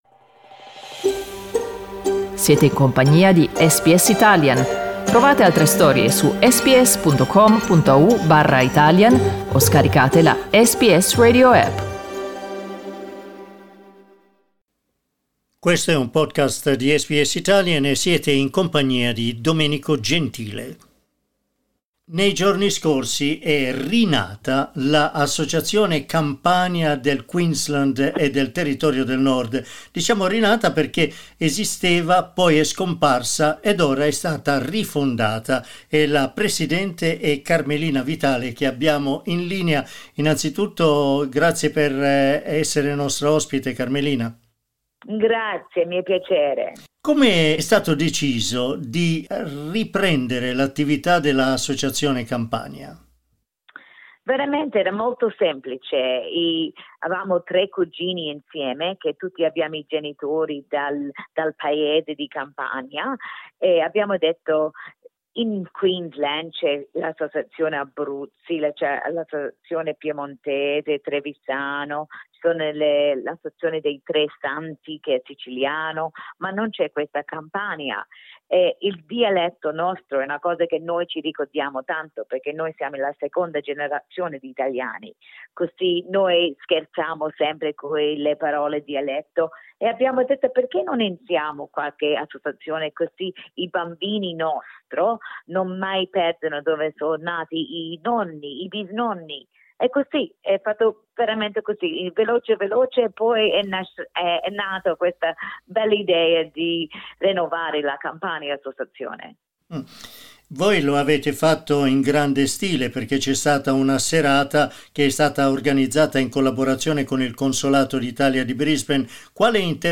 Ascolta l'intervista: LISTEN TO Rifondata l'Associazione Campania del Queensland SBS Italian 11:59 Italian Le persone in Australia devono stare ad almeno 1,5 metri di distanza dagli altri.